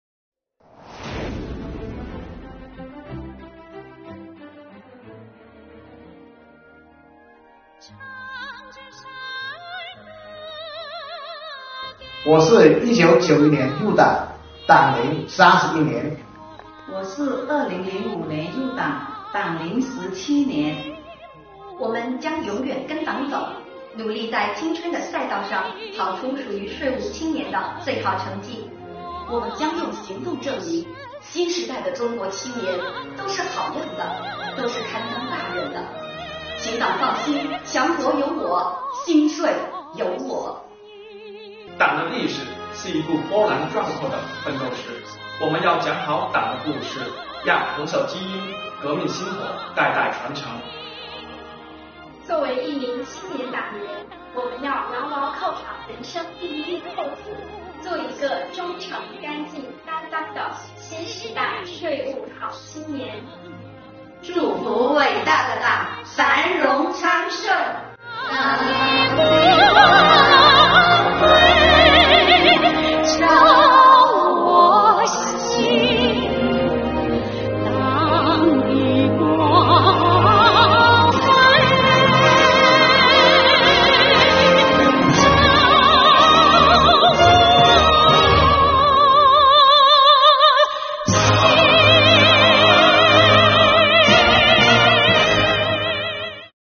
值此中国共产党成立101周年之际
来宾税务人以我初心，对党表白
让我们一起唱响党的赞歌，祝愿我们的党